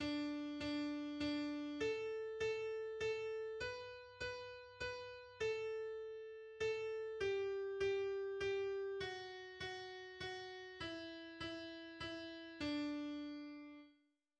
Gretel Pastetel ist ein deutschsprachiges und satirisches Volkslied in Dialogform.
Melodie